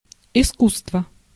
Ääntäminen
Ääntäminen US : IPA : [ˈkʌ.nɪŋ]